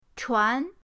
chuan2.mp3